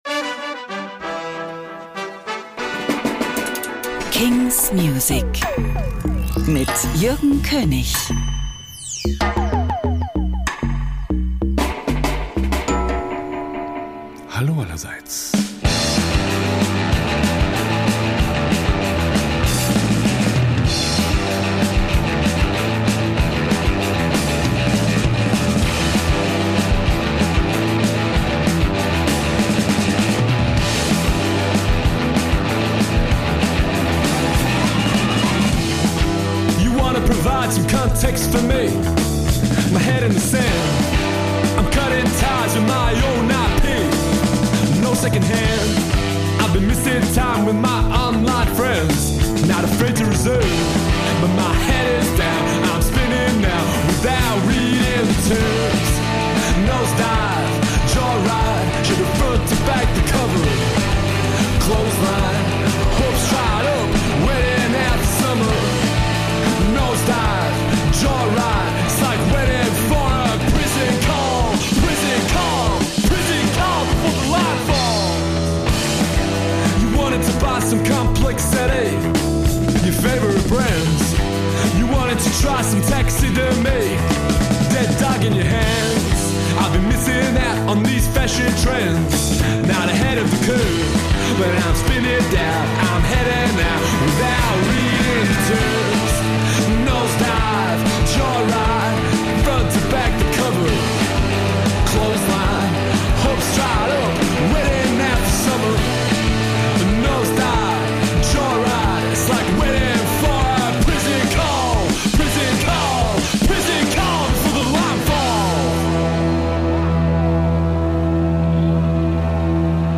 indie & alternative releases